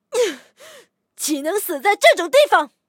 LT-35中破语音.OGG